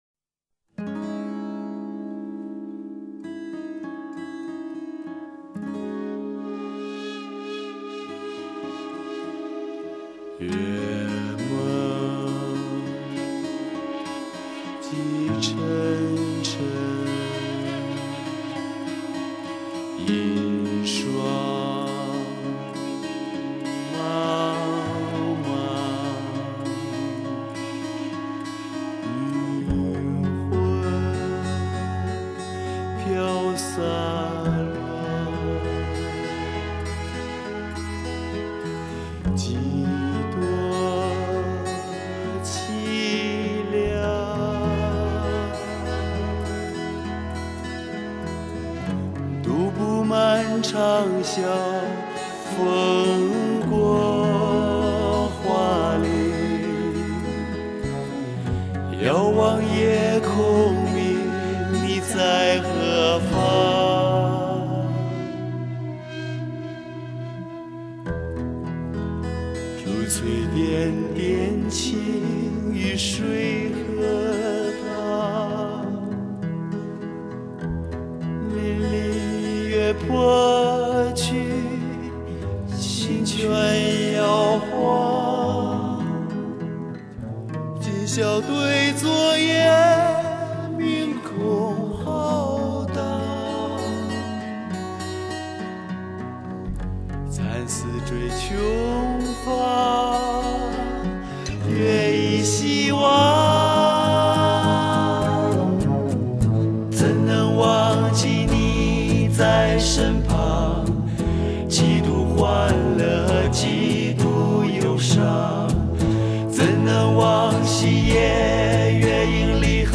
木吉他和电吉他的Solo